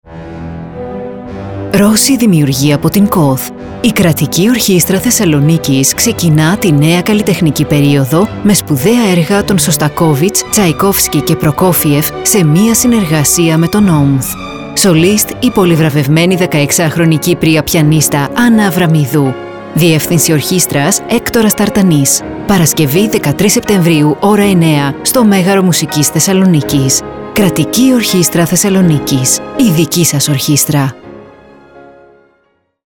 Ραδιοφωνικό σποτ 13σεπ24_Ρώσοι δημιουργοί.mp3